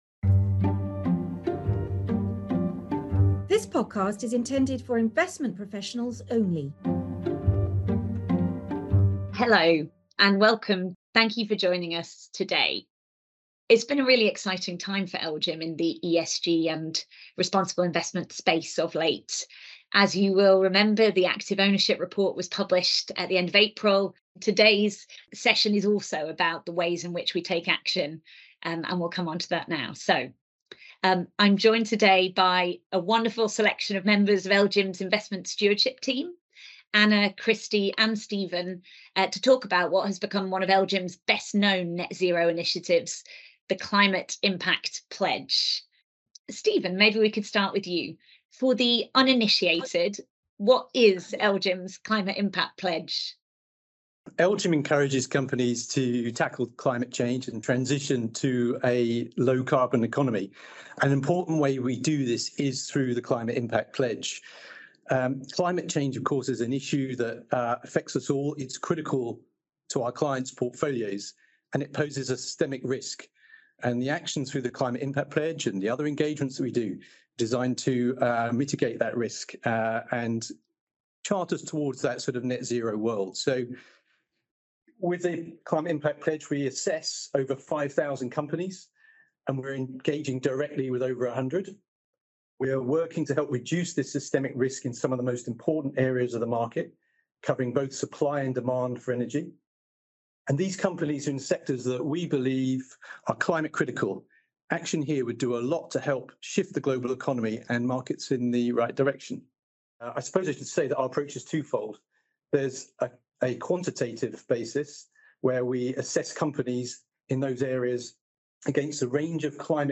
Over the past year, we zeroed in on lobbying and biodiversity and continued to push for companies to set and meet climate targets. In this podcast, taken from a live session